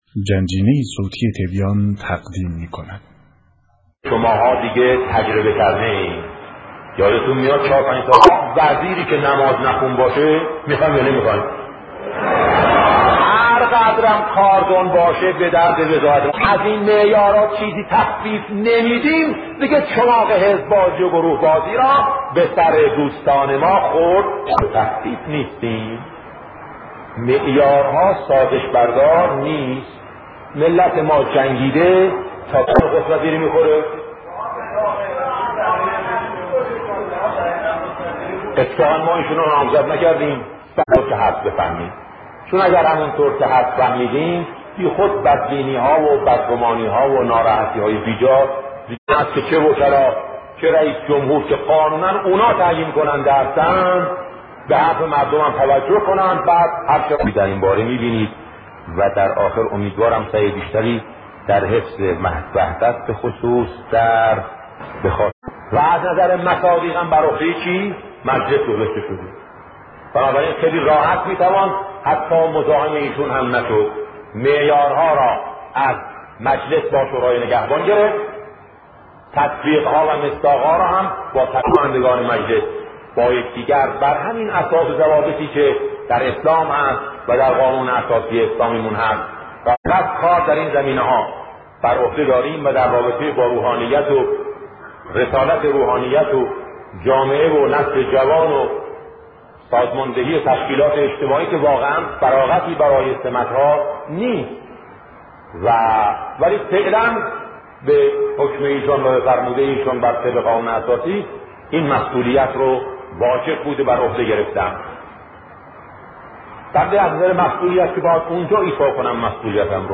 سخنرانی شهید بهشتی (ره)-چه کسی می تواند ولی فیه باشد؟